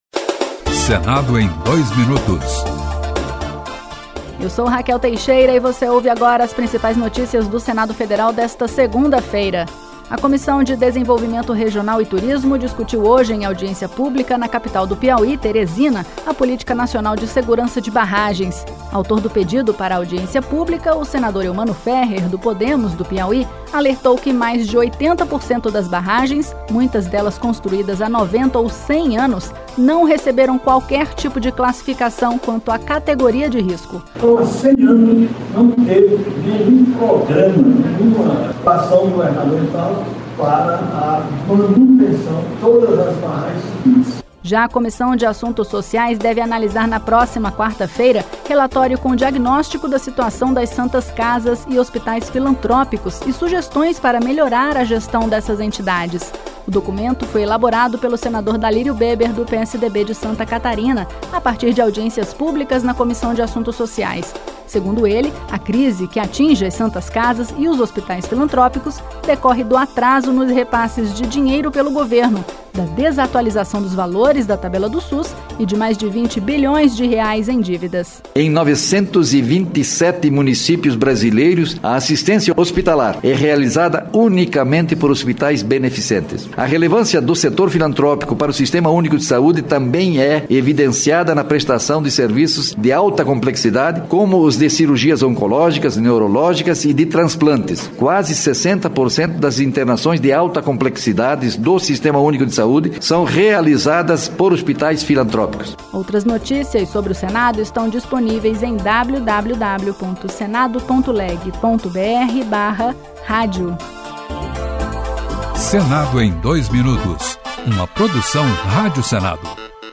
Um resumo das principais notícias do Senado